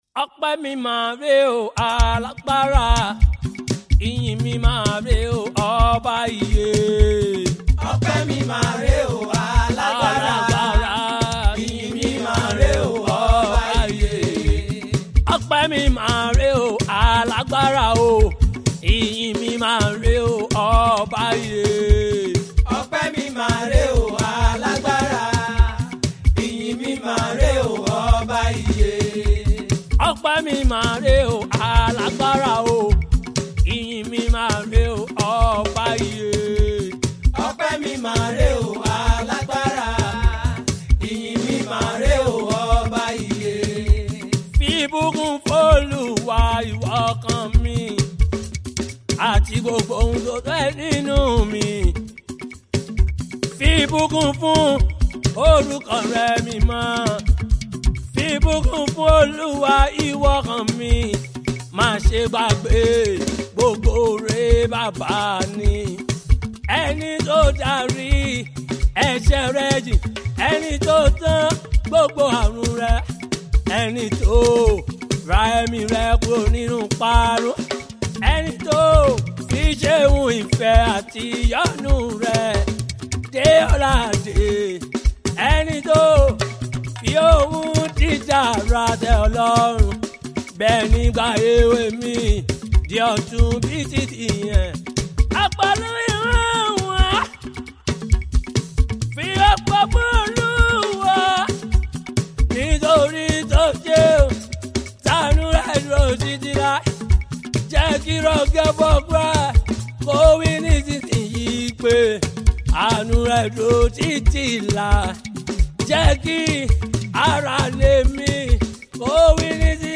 At the beginning of my ministry as a Fuji Gospel Artiste
mix of Fuji and Praise